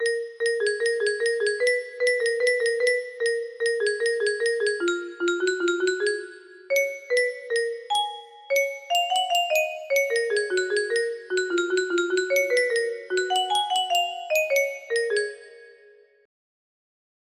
placeholder title music box melody